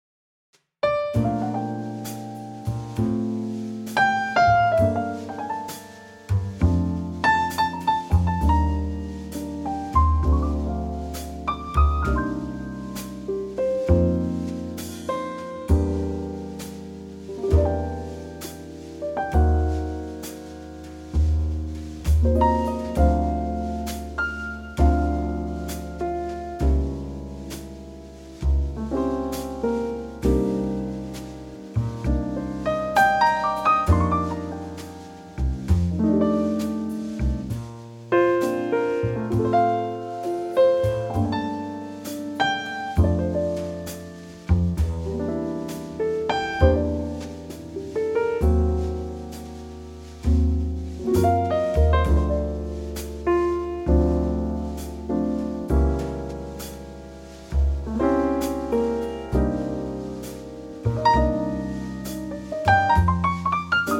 key - Bb - vocal range - C to Eb
Here's a superb Trio arrangement.